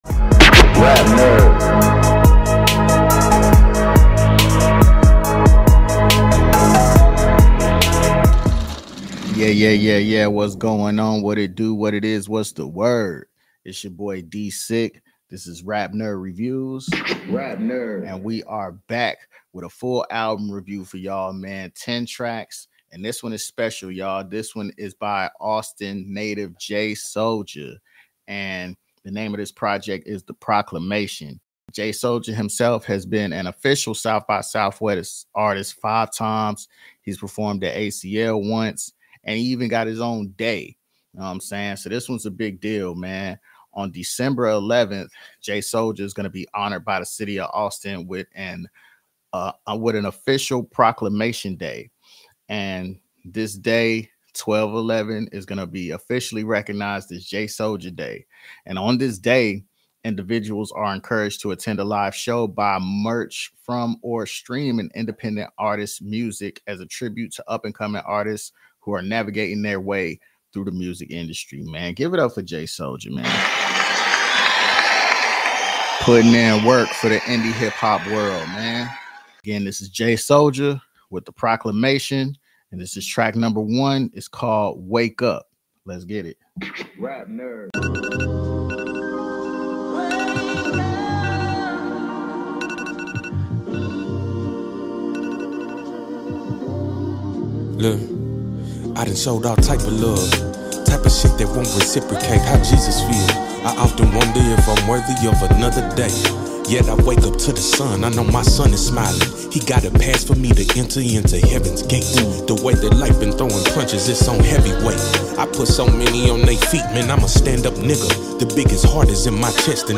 Rap Nerd Reviews Episode 23 | Indie Hip Hop Music Review Show.